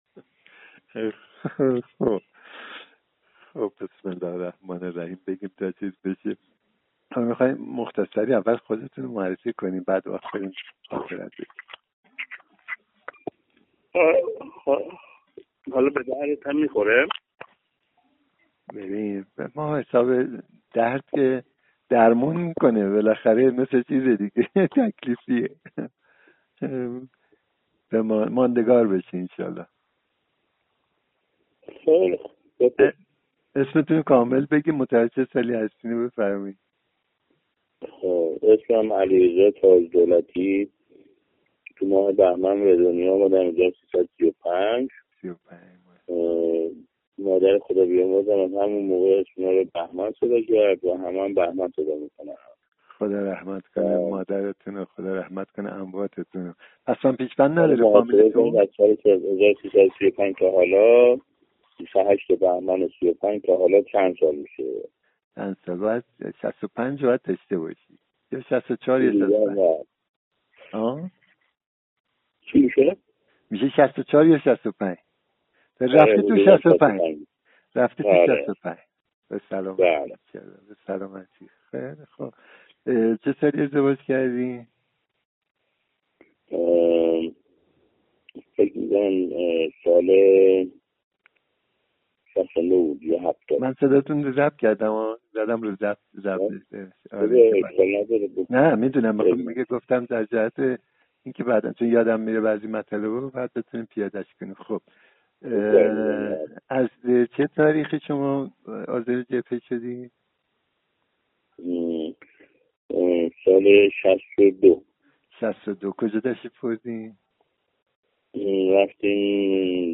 در یک گپ و گفت دوستانه بمناسبت تبریک نوروز سال ۱۴۰۰ برادر رزمنده و جانباز دقاع مقدس از خاطرات خود در جبهه ، مجروحیت ، انتقال به عقب ، عمل جراحی و…. نقل می کند .